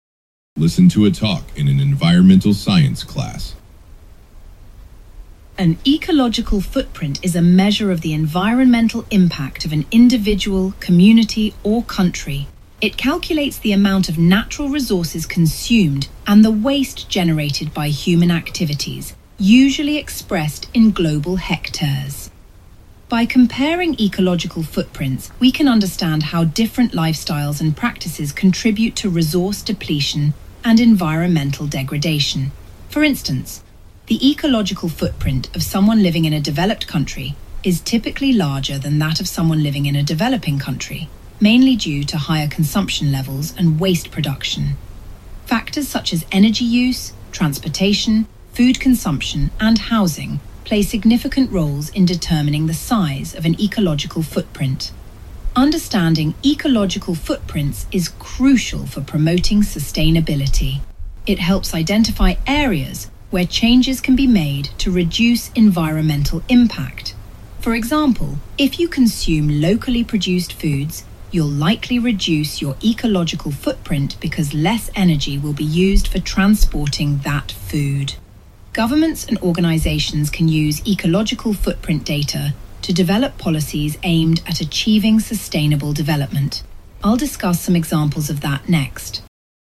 現行のTOEFLの講義では、教授が話す途中で学生が割って入って発言したり、質問することもありますが、新TOEFLでは、おそらく教授1人が最後まで話し続けると思われます。